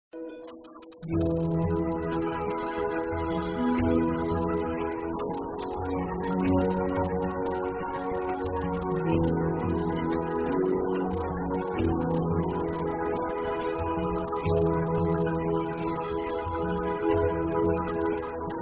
Shri Krishna basuri